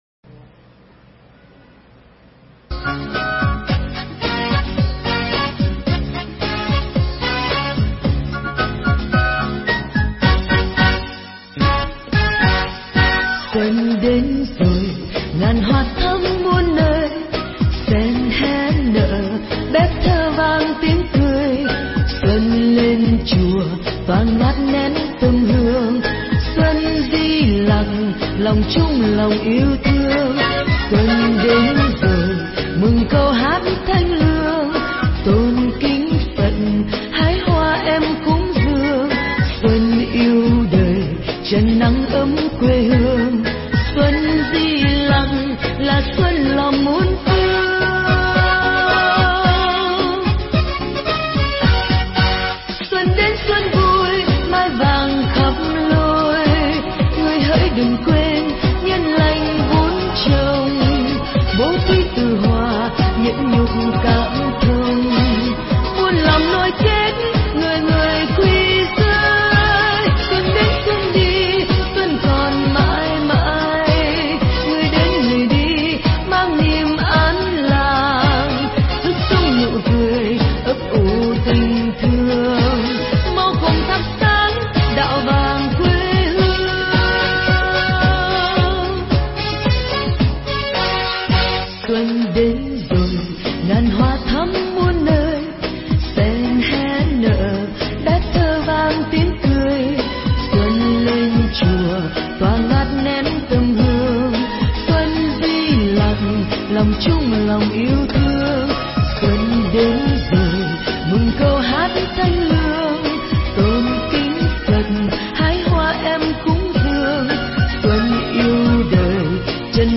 pháp thoại Chất Liệu Làm Nên Mùa Xuân
giảng nhân ngày Tết năm Canh Dần - 2010 tại chùa Phước Duyên (tp.Huế)